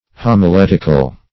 Homiletic \Hom`i*let"ic\, Homiletical \Hom`i*let"ic*al\, a. [Gr.